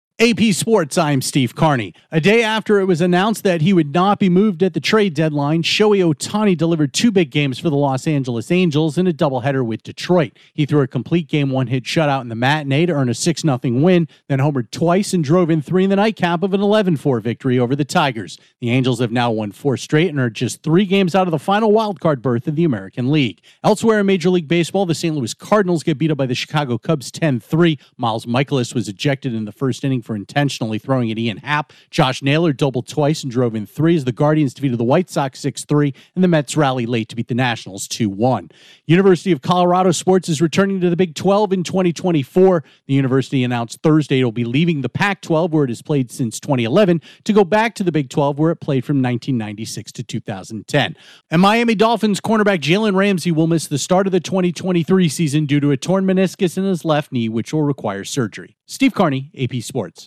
Shohei Ohtani has a monster day for the Angels, Cardinals starter Miles Mikolas gets ejected for intentionally hitting an opponent, the University of Colorado plans to head back to a familiar conference and a new Dolphin won't be ready for the start of the NFL season. Correspondent